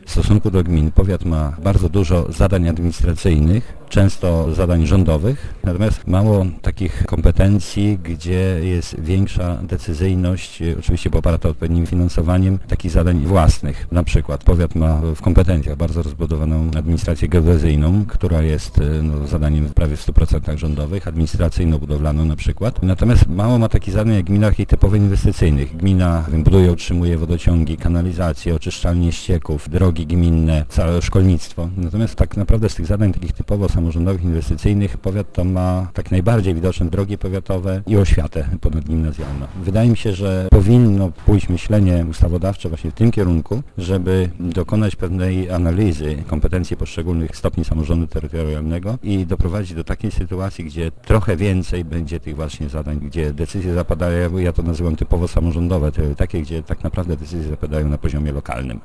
Powiat Lubelski: Sesja rady z okazji samorządowego święta
Starosta lubelski Paweł Pikula w swoim wystąpieniu mówił o "Rozwoju samorządności i zmianie kompetencji gmin i powiatów":